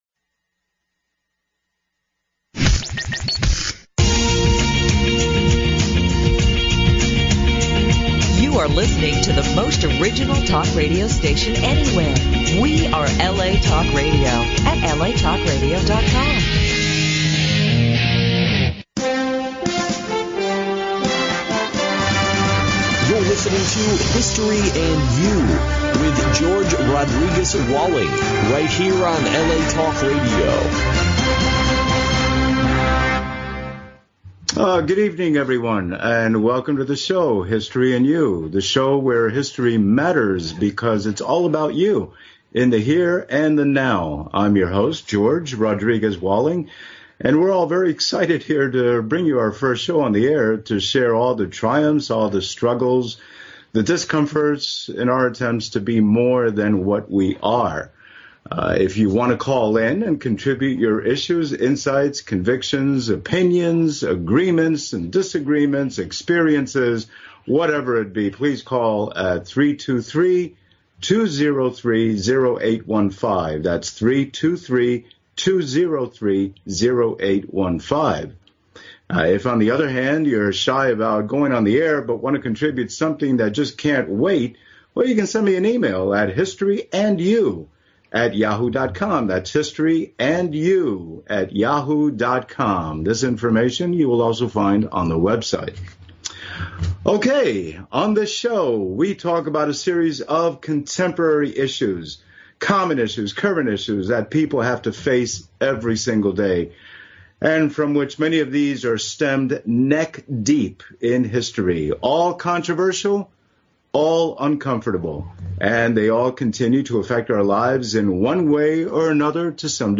Debut of History and You talk show, December 11, 2012